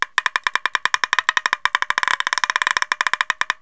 pecker.wav